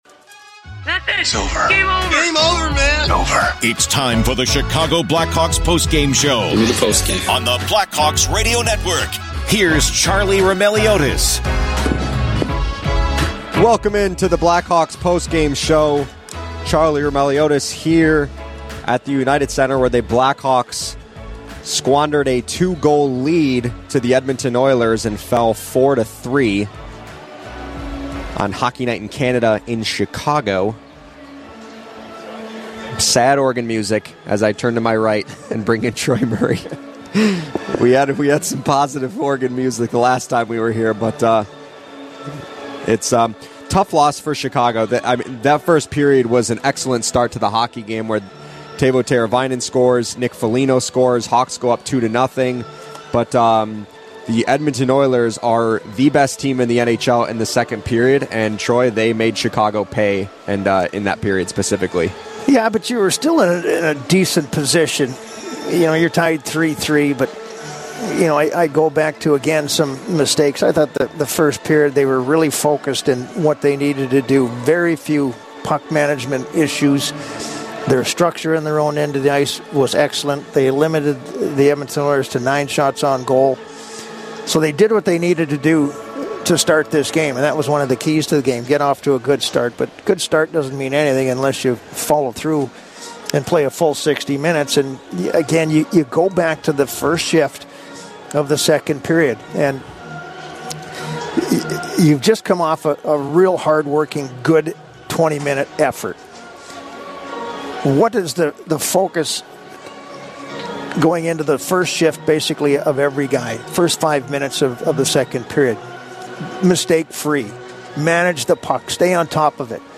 Later in the show, hear postgame sound from Jason Dickinson, Alec Martinez, and Anders Sorensen.